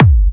VEC3 Bassdrums Trance 40.wav